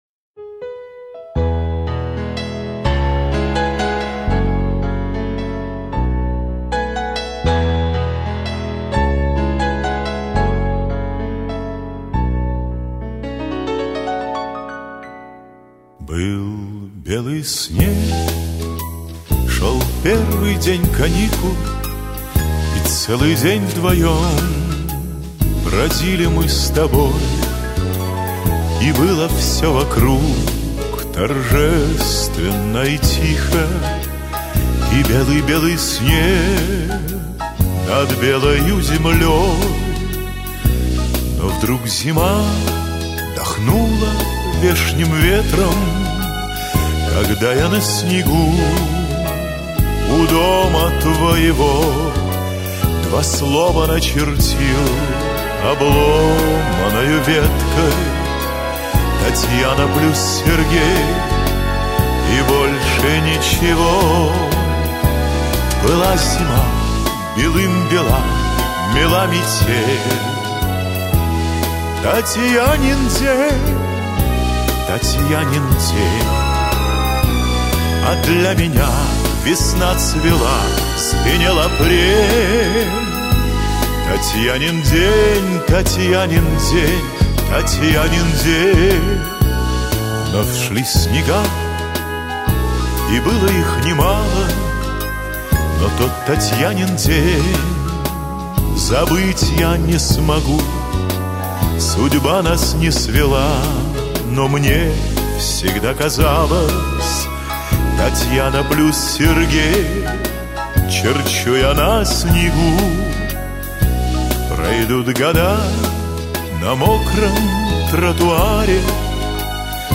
Песня